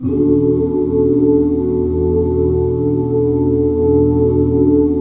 ESUS13 OOO.wav